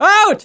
umpire_m_out03.mp3